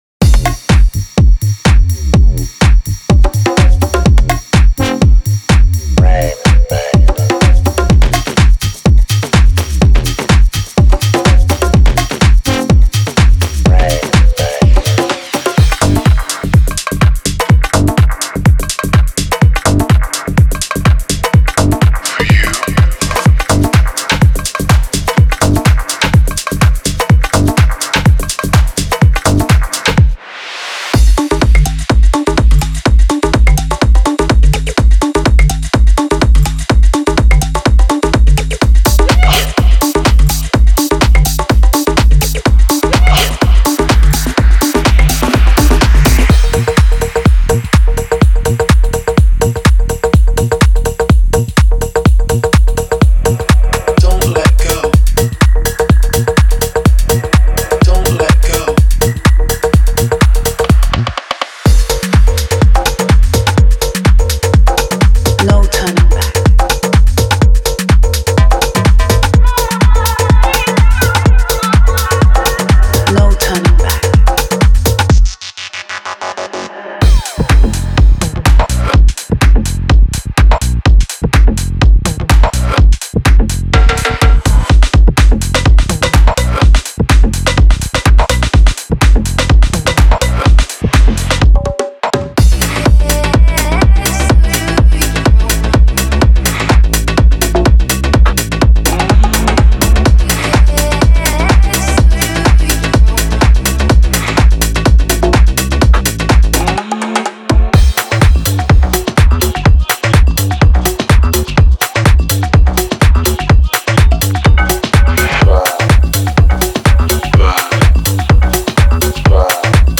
TB303 Loops -> 狭いアシッドループと歪んだTB303サウンドでリズム感を完璧に捕えたサウンド。
デモサウンドはコチラ↓
Genre:Tech House